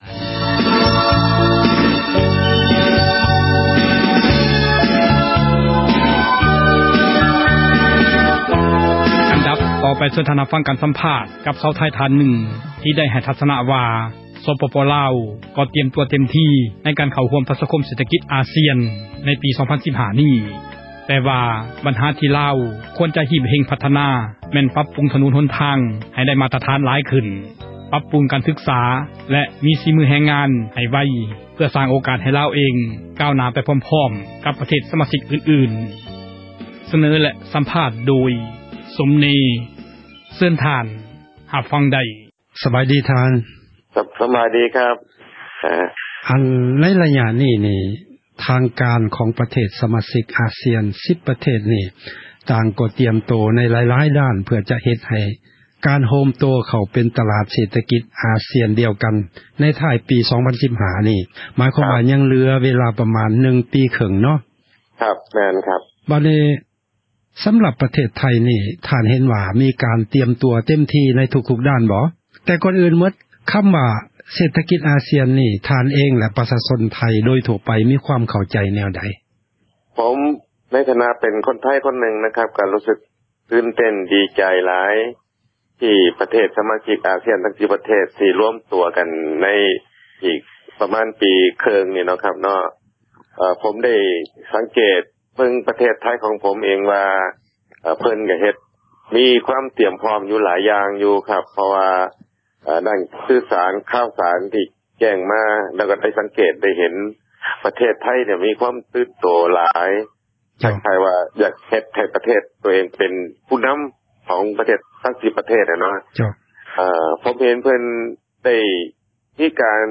ເຊີນທ່ານ ຮັບຟັງ ການສຳພາດ ຊາວໄທ ທ່ານນຶ່ງ ທີ່ໄດ້ ໃຫ້ ທັສນະ ວ່າ ສປປລາວ ກໍຕຣຽມຕົວ ເຕັມທີ ໃນການ ເຂົ້າຮ່ວມ ປະຊາຄົມ ເສຖກິດ ອາຊຽນ ໃນປີ 2015.